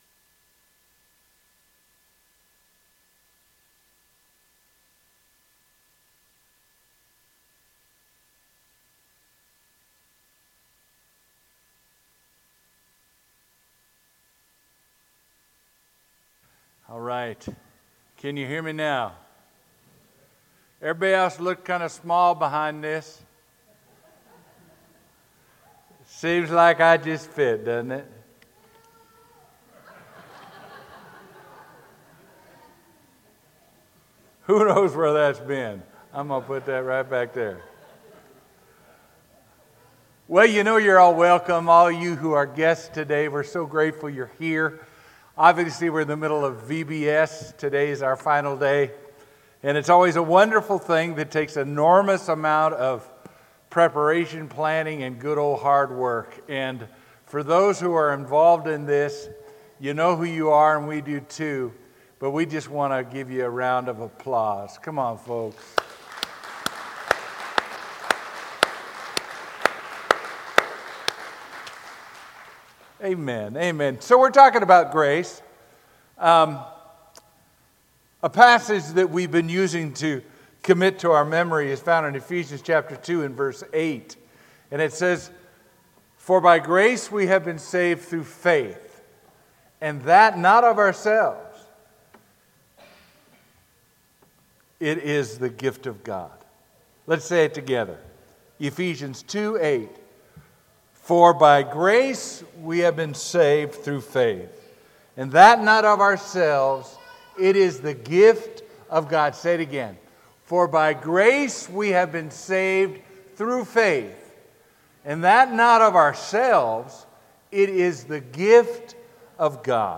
Sermon: “His Grace Reaches Me”